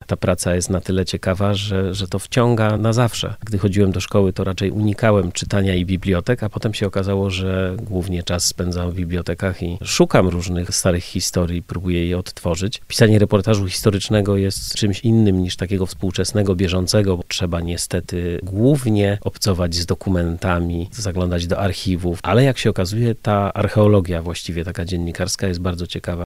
W siedzibie Radia Lublin trwa 8. Festiwalu Reportażu.